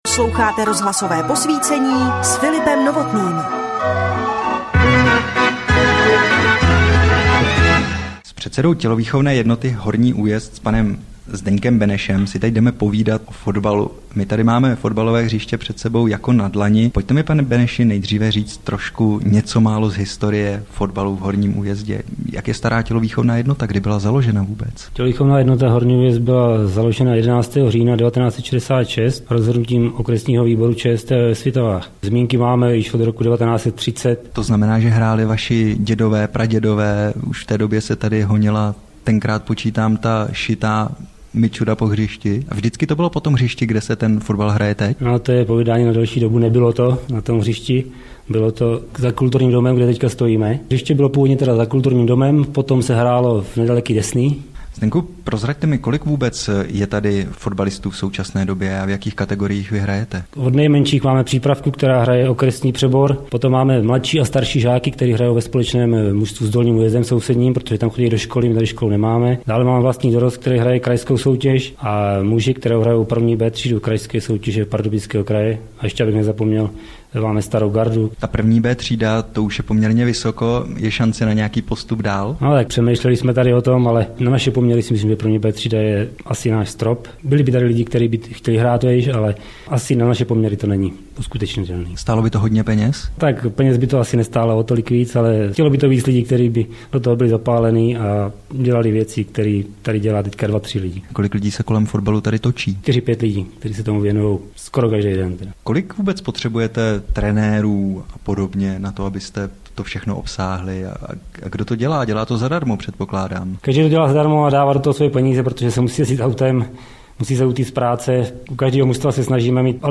Záznam z vysílání Českého rozhlasu